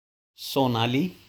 Sonali (So-NAA-lee)